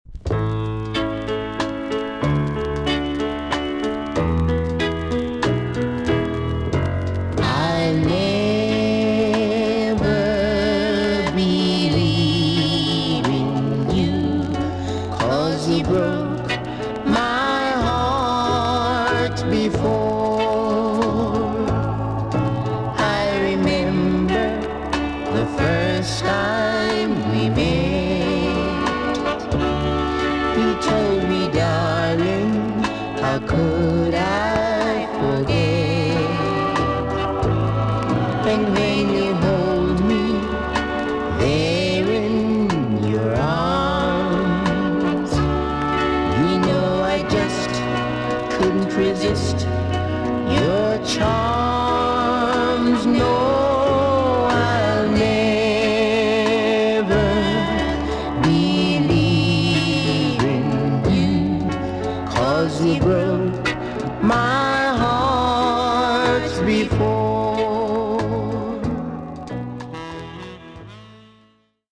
TOP >SKA & ROCKSTEADY
VG+ 少し軽いチリノイズが入ります。